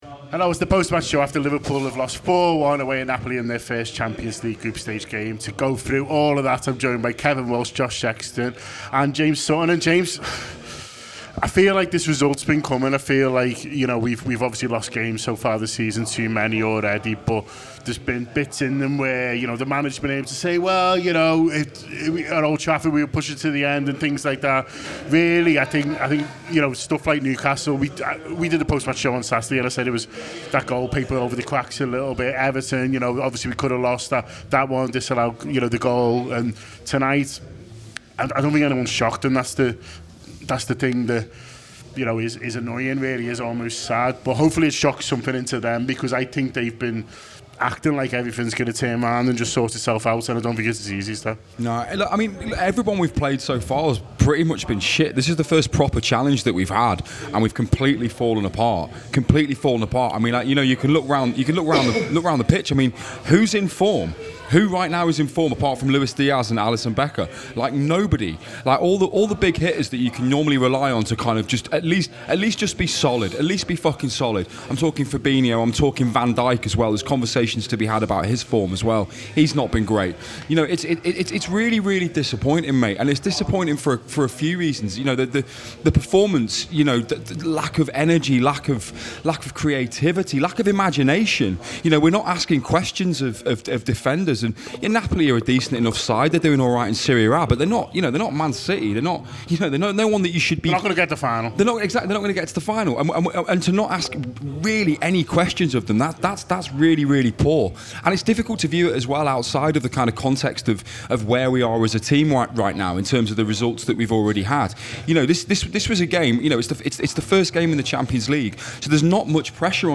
Below is a clip from the show – subscribe for more Napoli v Liverpool reaction…